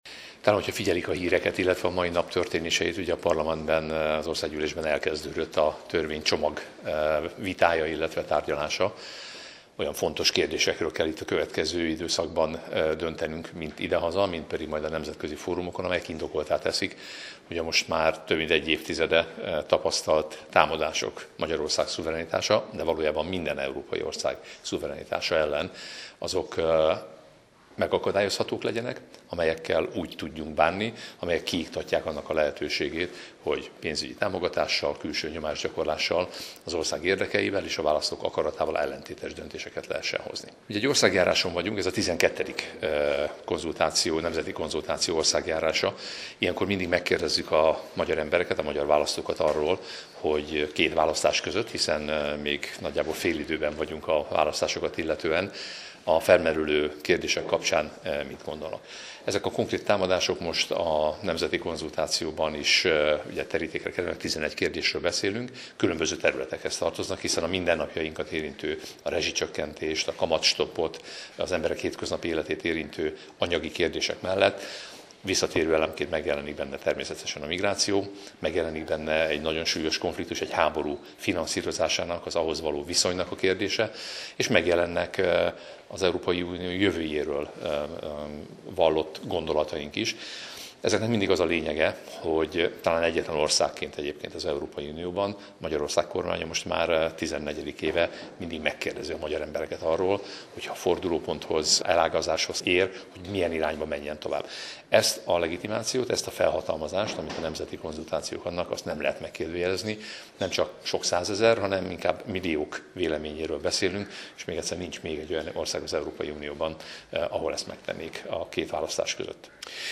Megtelt a Placc Közösségi Ifjúsági Tér a Fidesz "Védjük meg Magyarország függetlenségét!" című országjárás politikai fórumának idejére.